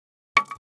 Index of /traerlab/AnalogousNonSpeech/assets/stimuli_demos/jittered_impacts/small_styrofoam_longthin_plank